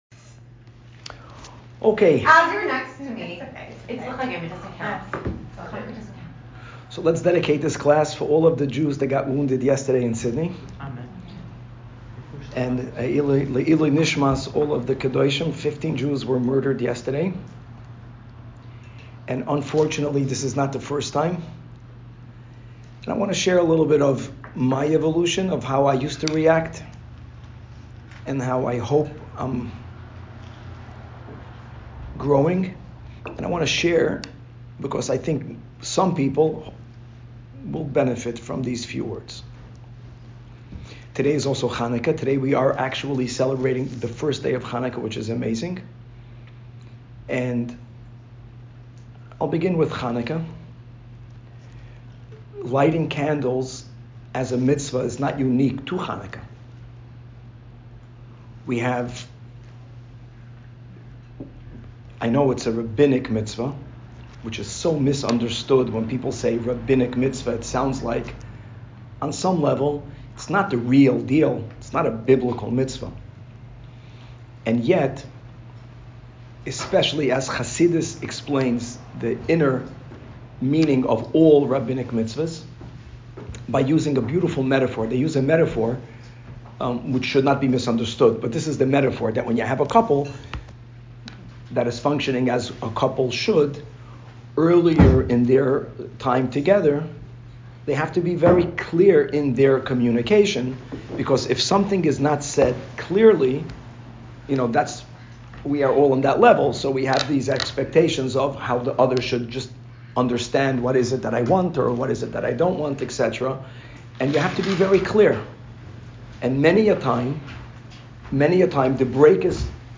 Woman's Class